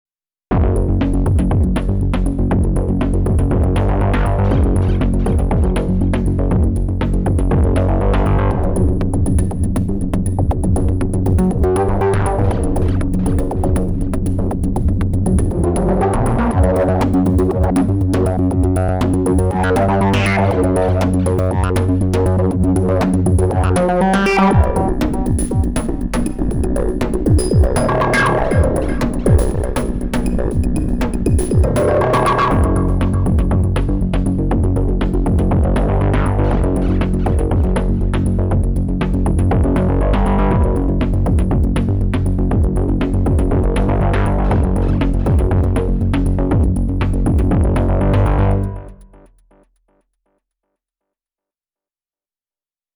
Industrial 90s throw back?